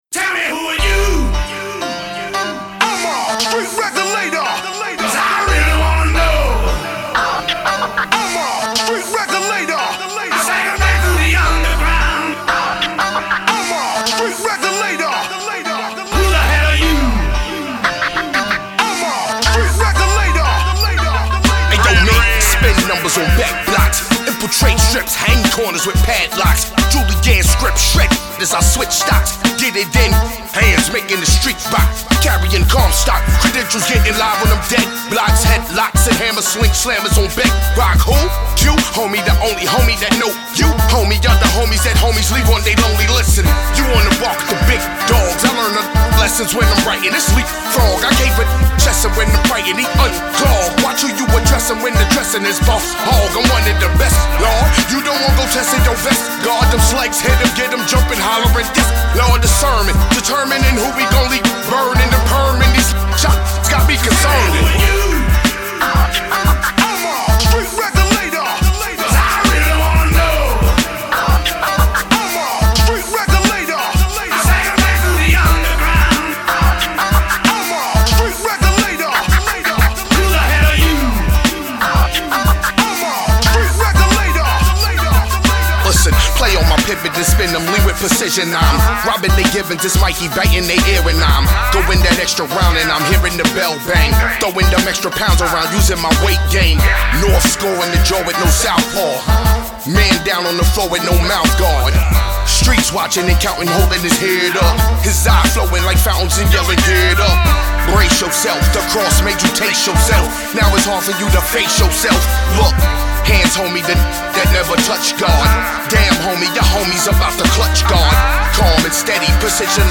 Brooklyn Spitter